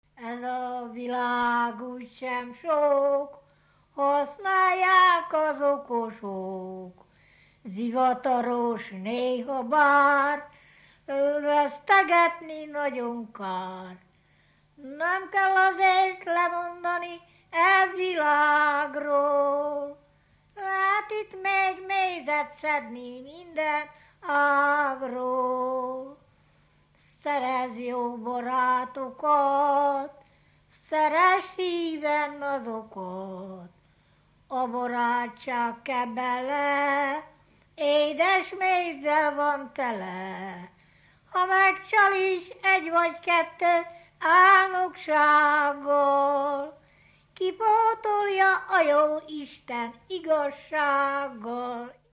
Dunántúl - Tolna vm. - Decs
Stílus: 8. Újszerű kisambitusú dallamok
Kadencia: 2 (2) 2 1